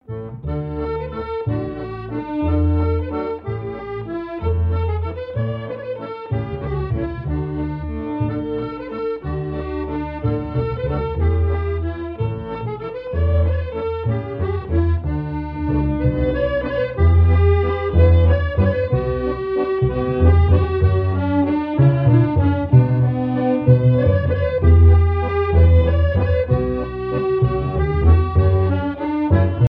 Marais Breton Vendéen
danse : valse